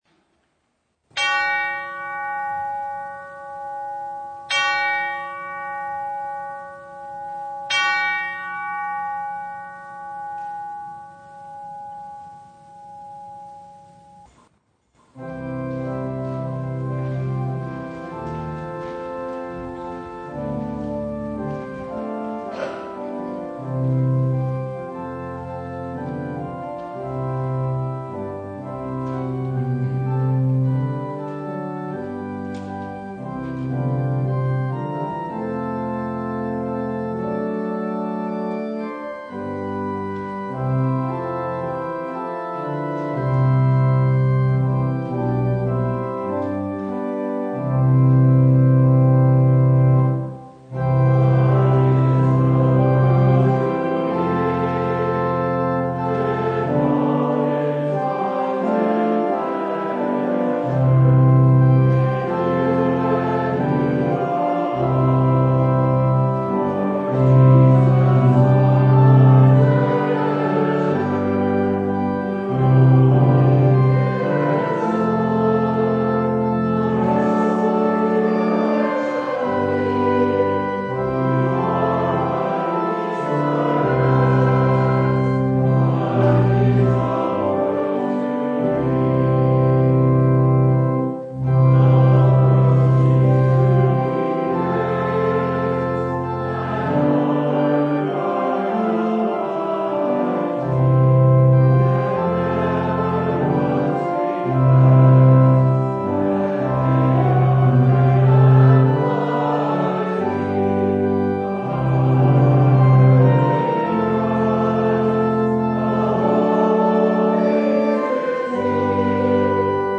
November 15, 2020 Twenty-fourth Sunday after Pentecost (audio recording) Passage: Matthew 25:21 Service Type: Sunday Trusting in the Lord and in His gracious work toward us in Christ, we are “good and faithful” stewards.